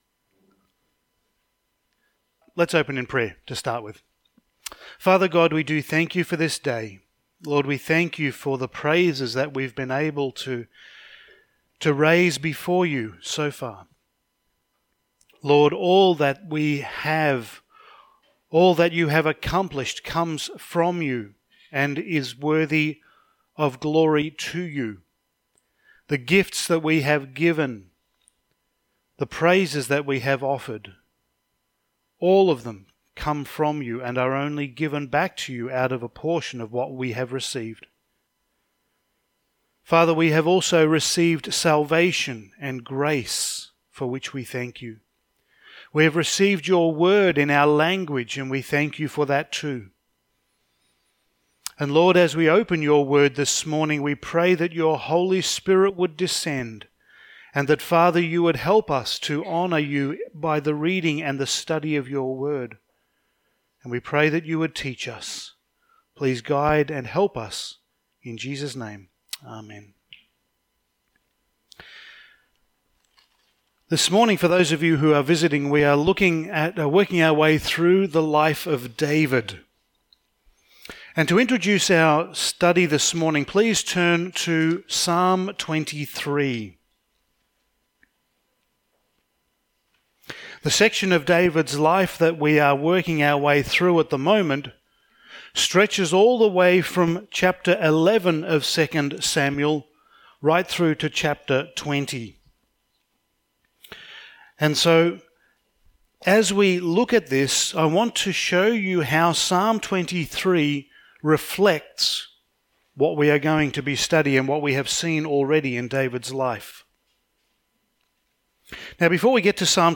Passage: 2 Samuel 16:1-23 Service Type: Sunday Morning